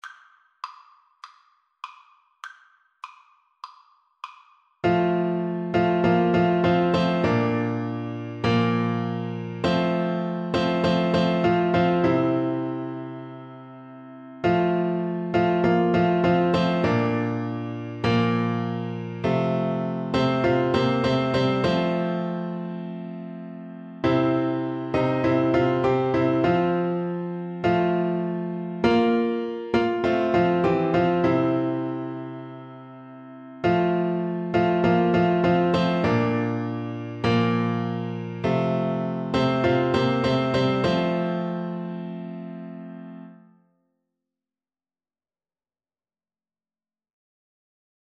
Christian
4/4 (View more 4/4 Music)
Classical (View more Classical Viola Music)